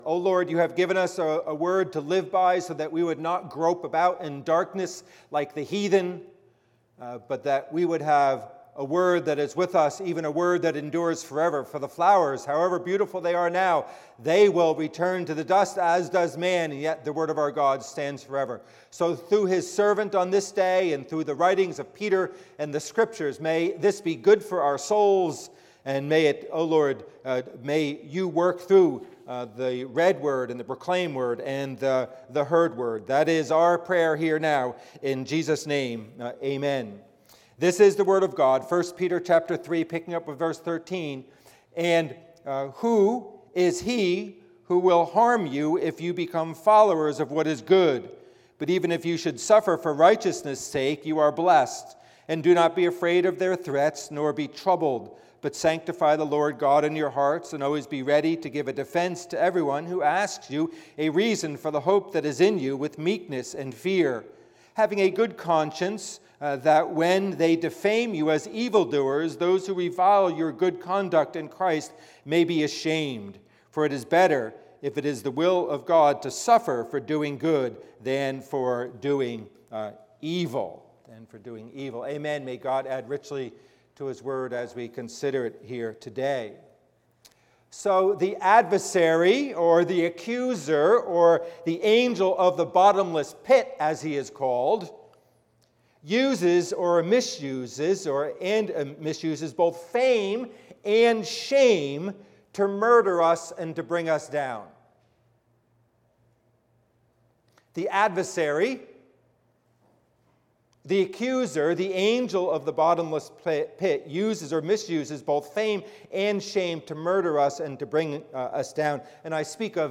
Passage: 1 Peter 3:15 Service Type: Worship Service